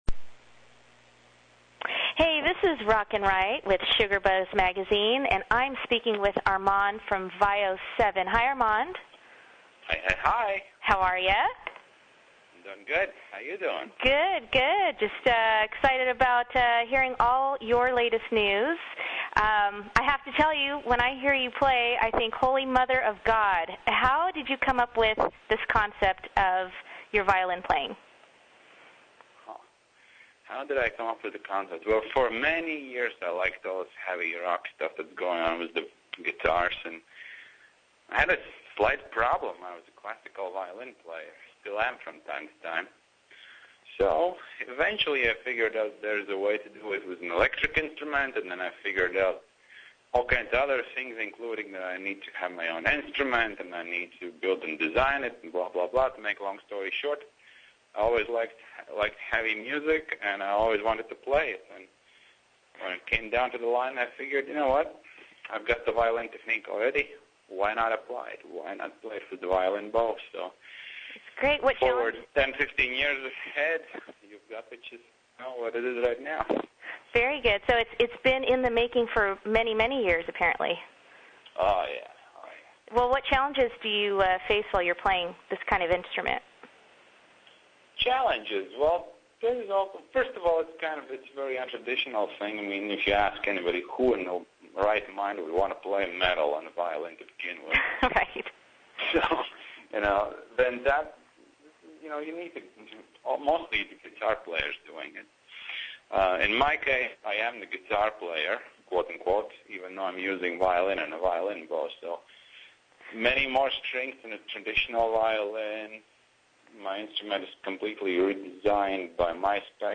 (or right click and select "save target as" to download) This is a 20 minute interview so it should download rather quickly.
vio7interview.mp3